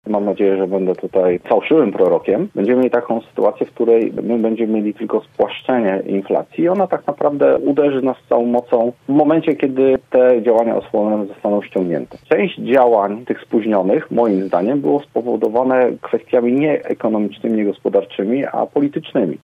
Nasi goście rozmawiali w programie Sobota po 9.